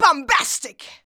BOMBASTIC.wav